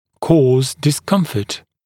[kɔːz dɪs’kʌmfət][ко:з дис’камфэт]вызывать дискомфорт, создавать дискомфорт